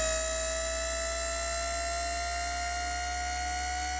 Camera Zoom In.wav